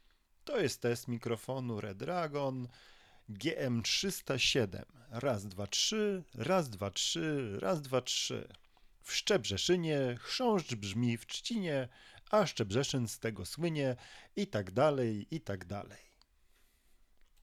Testy przeprowadziłem wyłącznie w trybie cyfrowym, który dla większości początkujących twórców będzie jedynym sposobem użytkowania.
Brzmienie Solary można określić jako „radiowe” i bliskie. Głos nabiera przyjemnego, niskiego osadzenia, co dodaje mu autorytetu. Należy jednak pamiętać, że w trybie USB mikrofon ma tendencję do lekkiego tłumienia wysokich tonów. Dźwięk jest wprawdzie czysty i pozbawiony szumów, ale nieco „ciemny”.
Test-Mikrofonu-Redragon-GM307.mp3